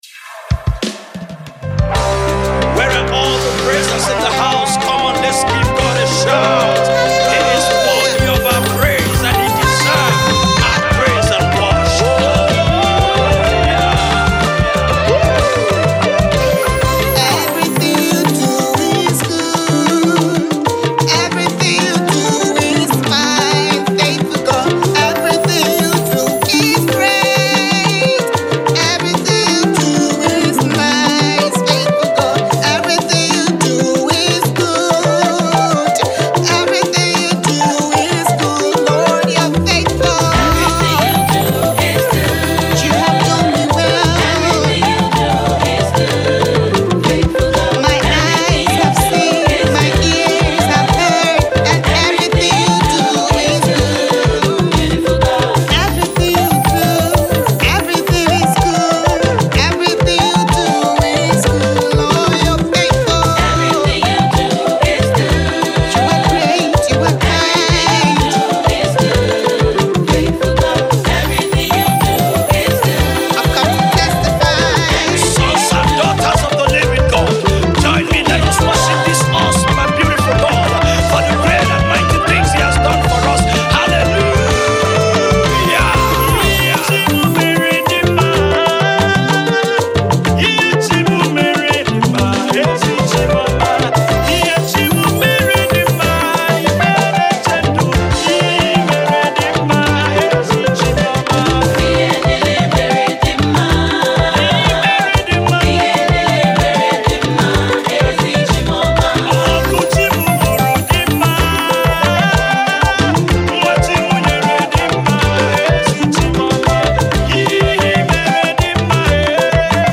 Abuja based Nigerian Christian music artist